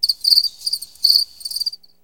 Crickets
Crickets.wav